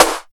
51 SNARE.wav